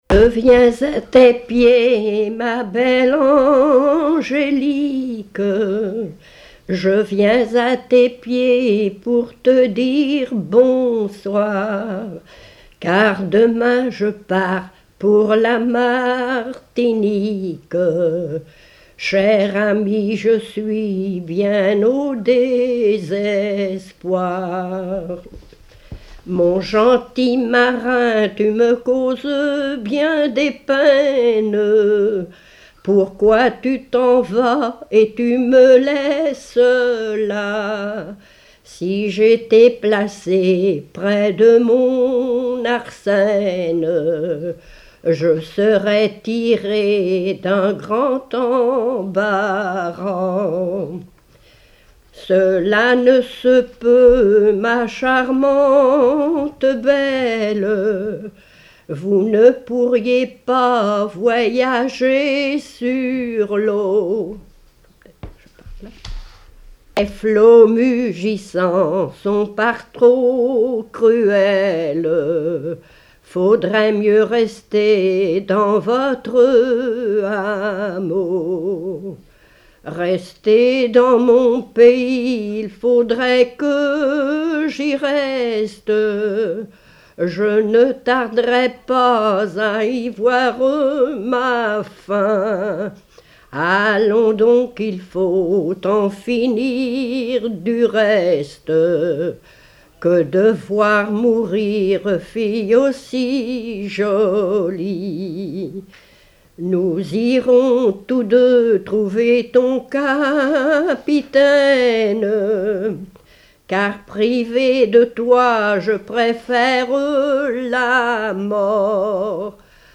circonstance : maritimes
Genre strophique
Pièce musicale inédite